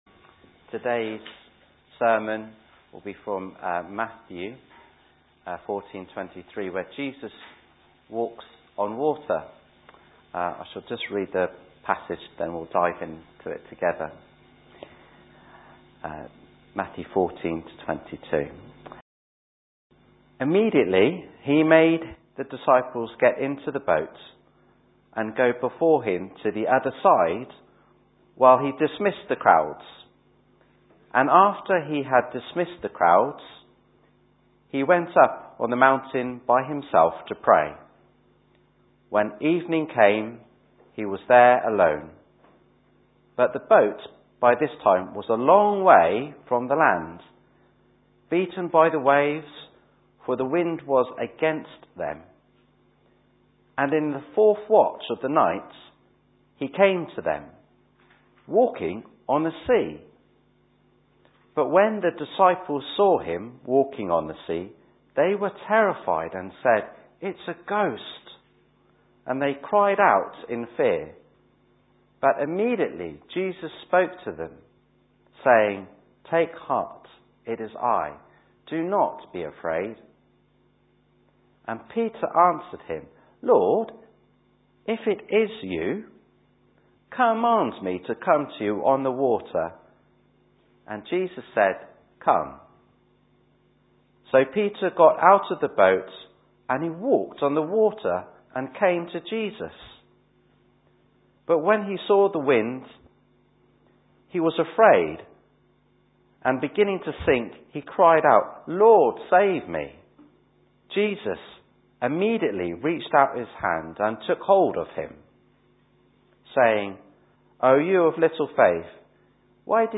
Recordings of weekly Sunday sermons from Billericay Baptist Church, Essex UK.